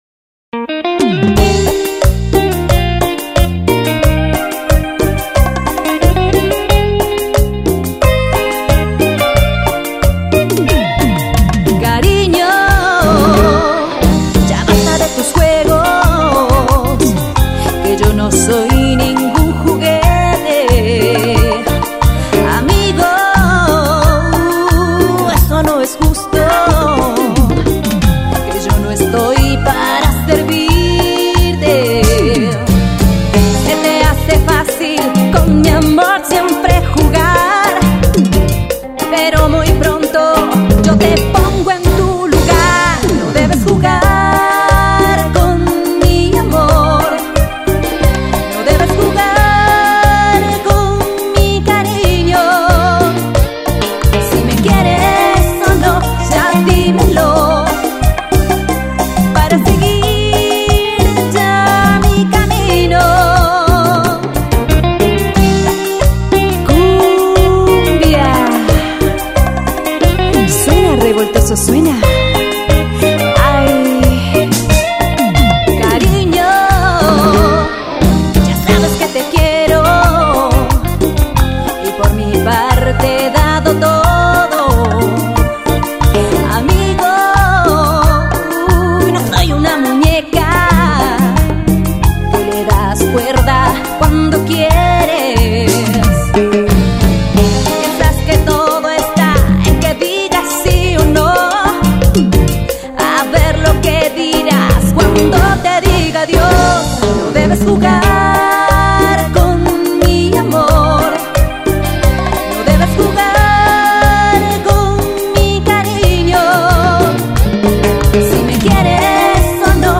Llegó el momento de hacer CUMBIA !!